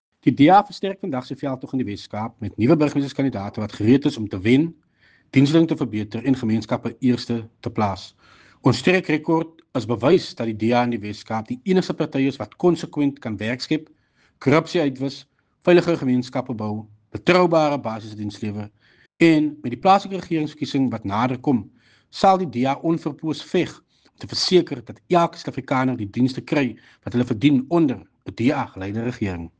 Note to editors: Please find attached soundbite in
Afrikaans by Tertuis Simmers